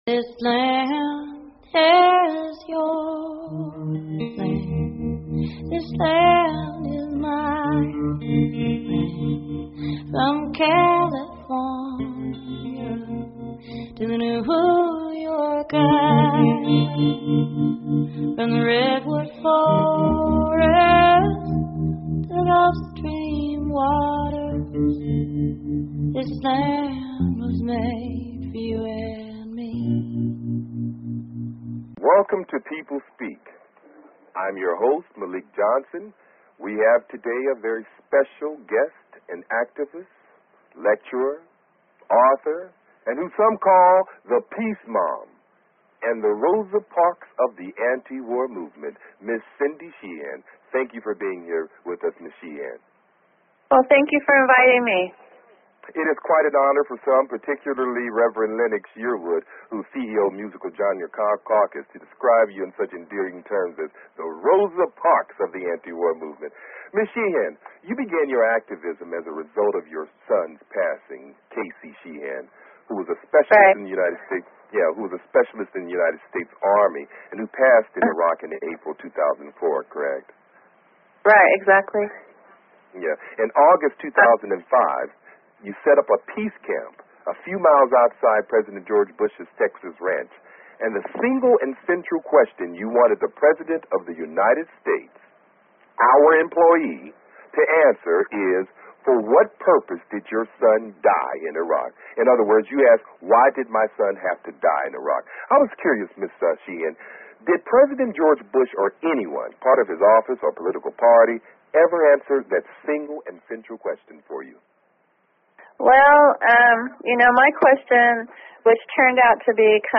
Talk Show Episode, Audio Podcast, The_People_Speak and Cindy Sheehan on , show guests , about , categorized as News,Politics & Government
Guest, Cindy Sheehan